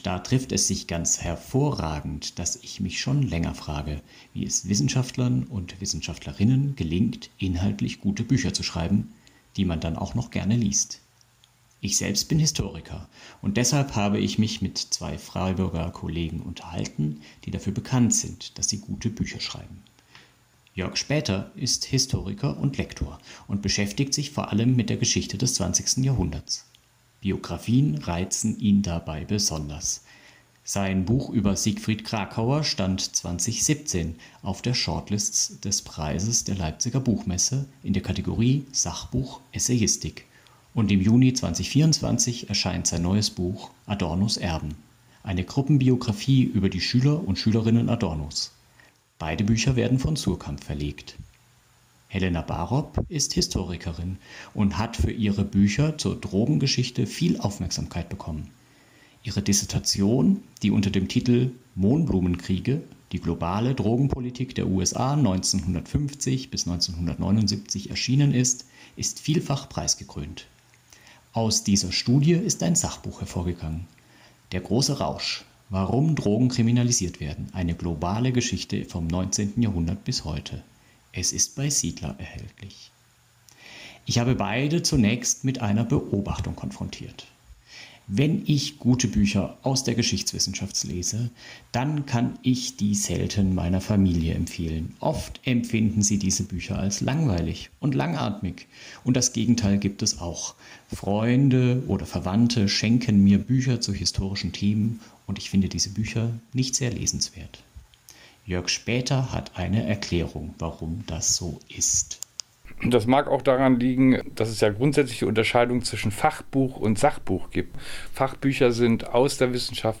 Interview | Was zeichnet ein gutes Sachbuch aus?